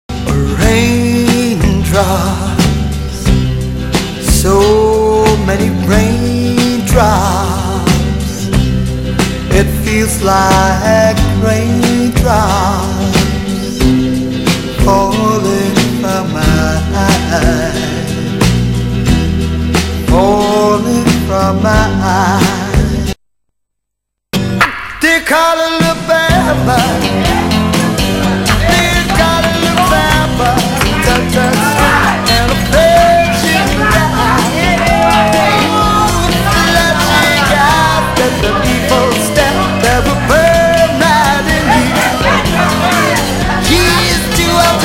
ストリングス等も配したアレンジで、バンドというより伴奏にソロ歌手が歌った趣のある仕上がり。
メンバーやファン（仲間内？）のガヤを取り入れた、モッド感、ライブ感のあるパーティーソングに仕上がっている。
(税込￥5280)   UK R&B